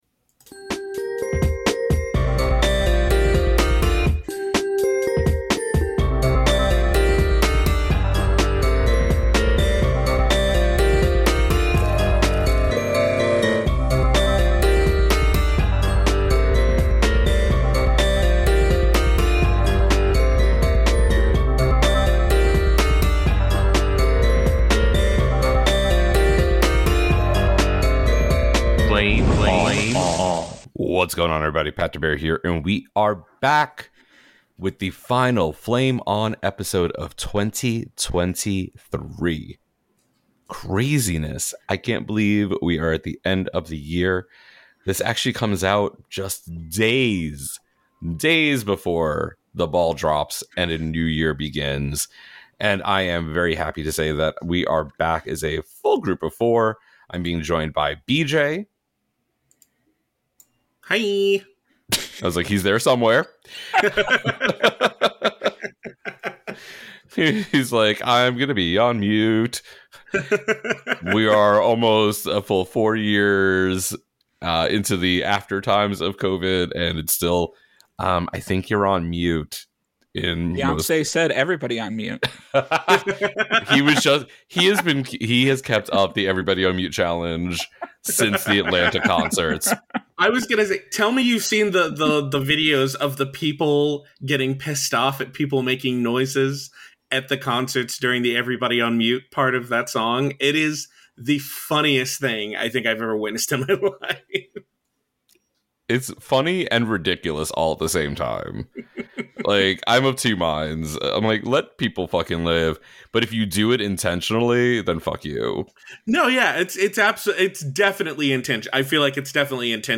Another year has come and gone and the boys are back at the round table to reminisce on the year in pop culture.